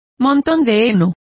Complete with pronunciation of the translation of haycock.